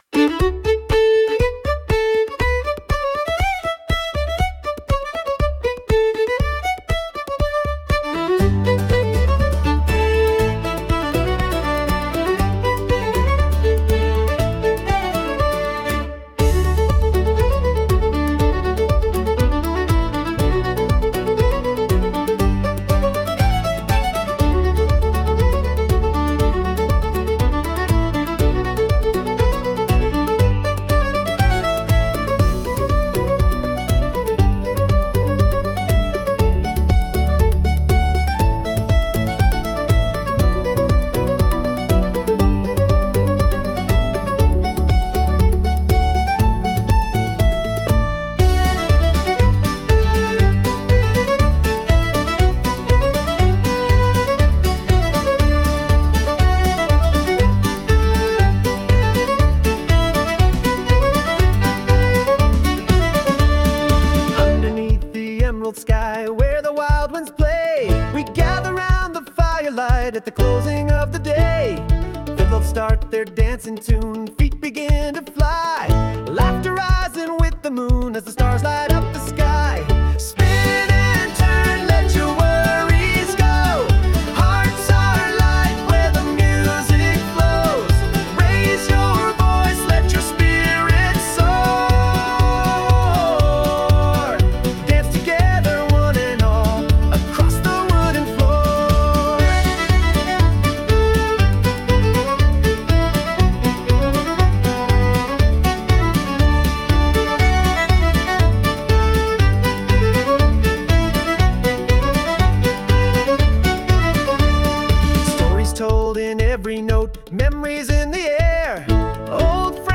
軽やかなフィドルとアコースティックギターが駆け抜ける、明るくエネルギッシュなケルト風の一曲。